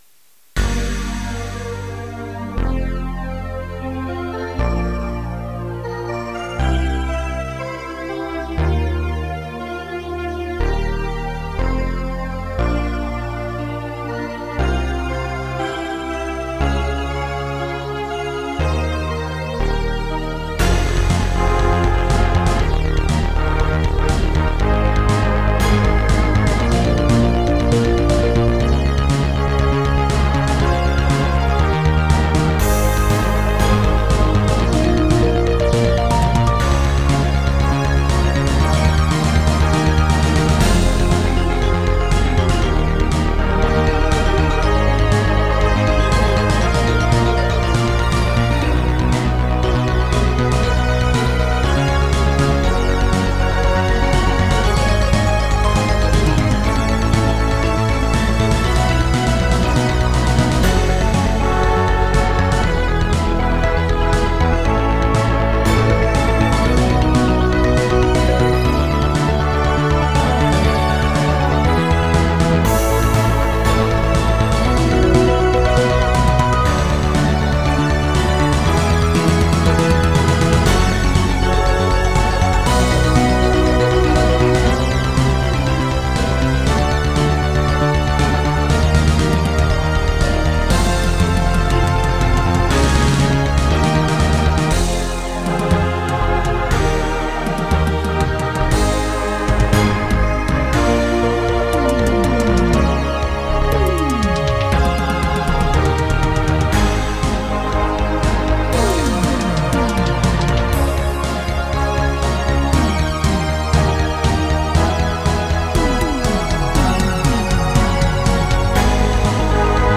EUROPOP MUSIC ; BALLADS